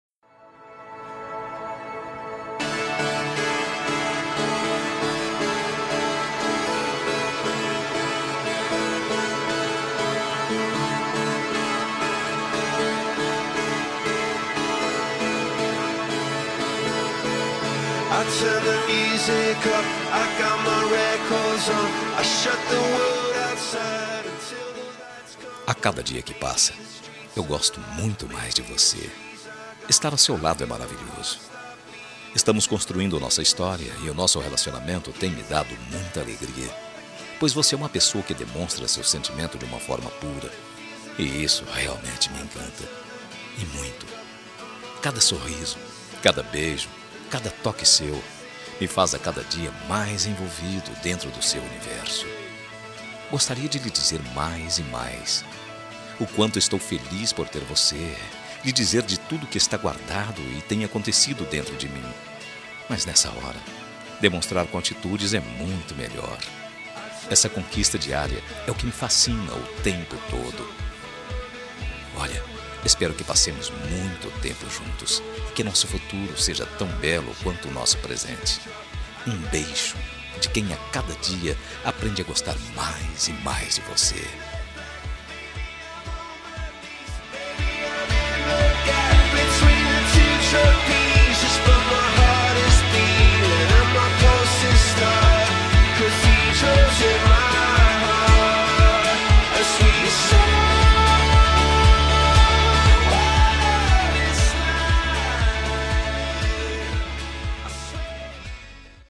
Telemensagem Romântica para Esposa – Voz Masculina – Cód: 202013